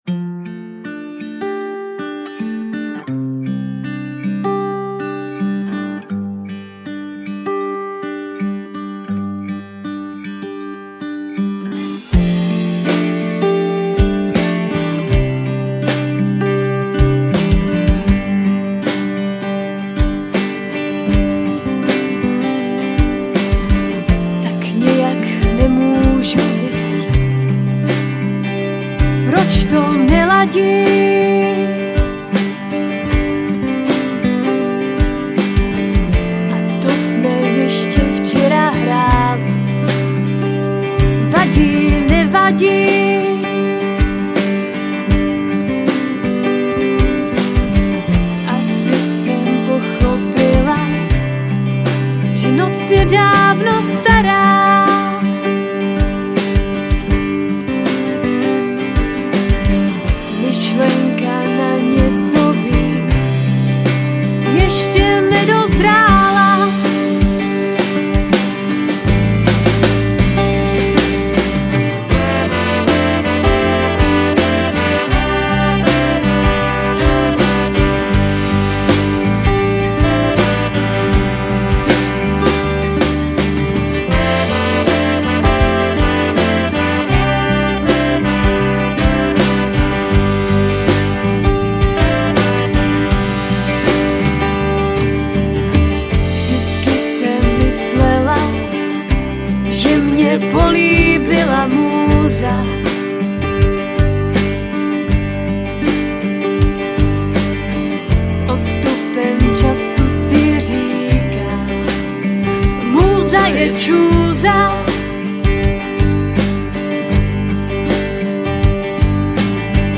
Žánr: Ska/Funk/Reggae